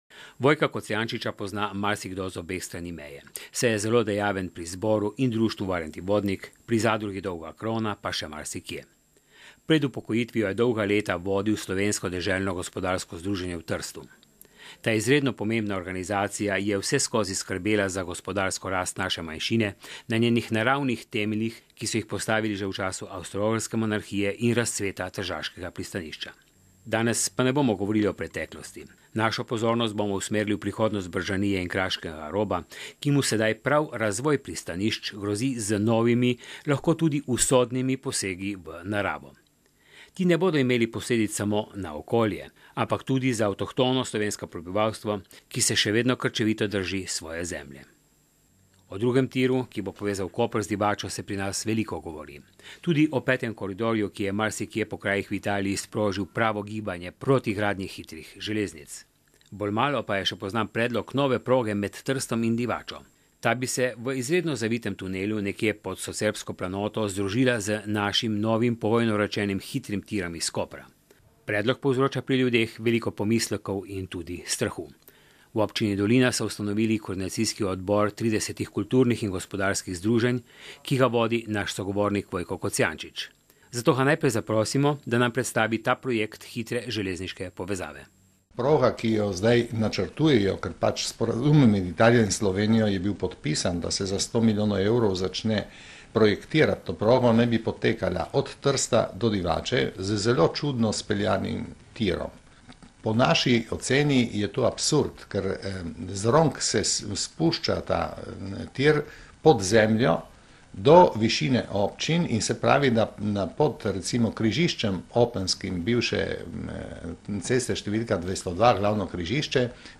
radijskemu pogovoru